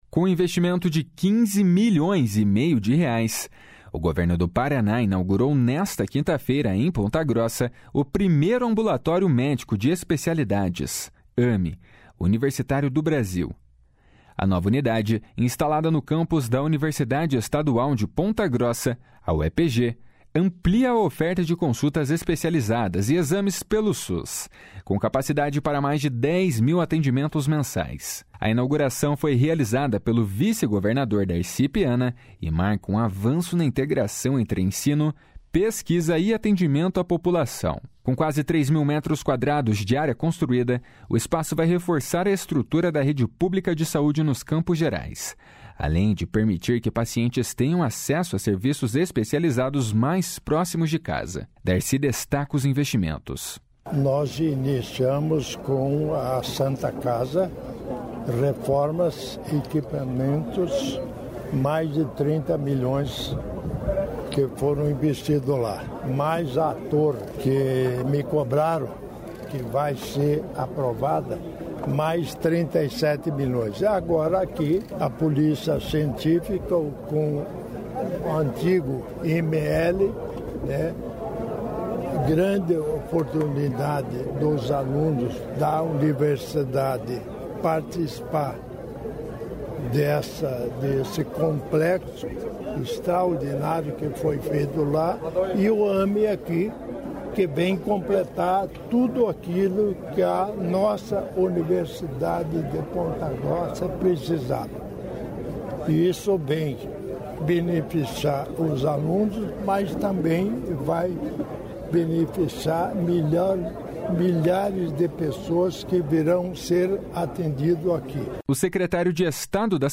O secretário de Estado da Saúde, Beto Preto, destacou o impacto da unidade na ampliação da rede de atendimento especializado e na regionalização dos serviços.
O secretário de Ciência, Tecnologia e Ensino Superior, Aldo Bona, enfatiza a ação.